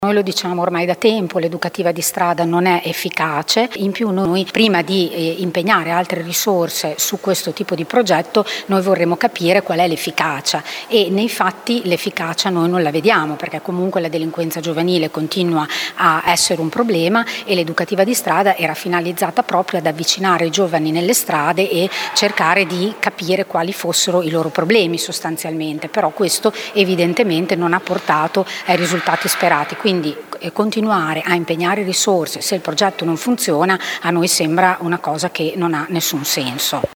Lo ha sostenuto ieri in consiglio comunale, durante la discussione della delibera per la variazione di bilancio, la consigliera di Fratelli d’Italia Elisa Rossini: